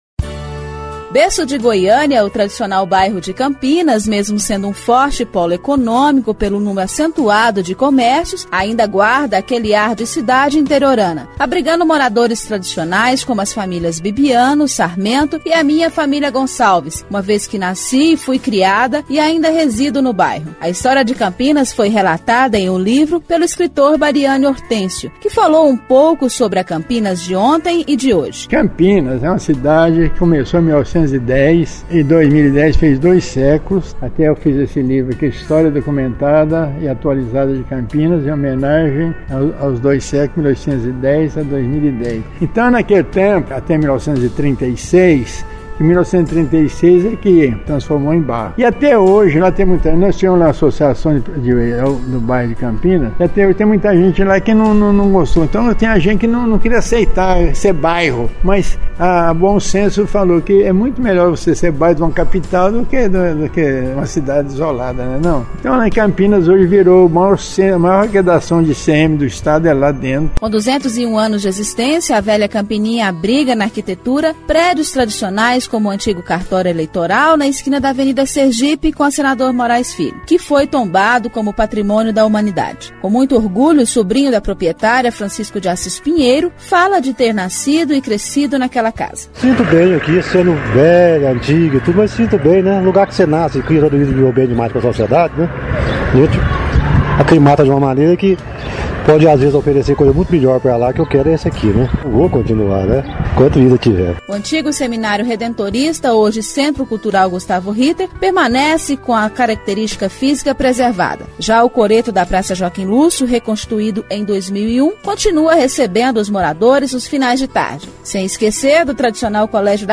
Acompanhe a matéria com a repórter